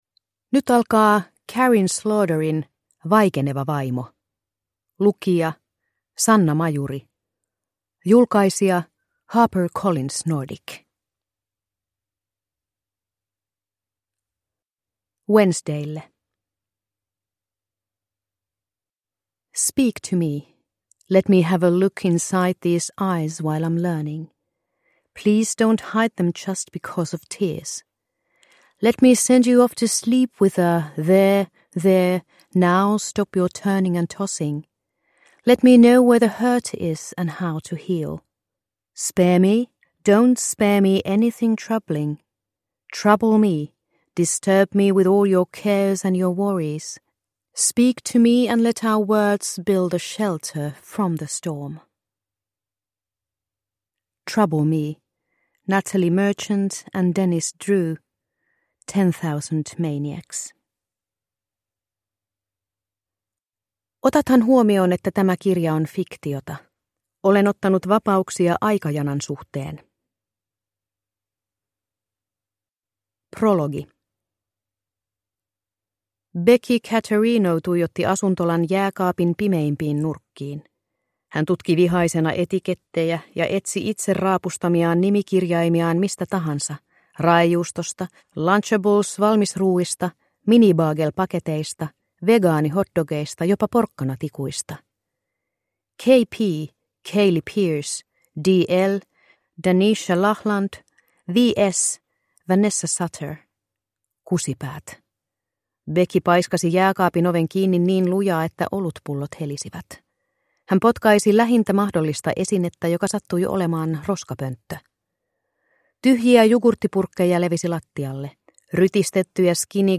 Vaikeneva vaimo – Ljudbok – Laddas ner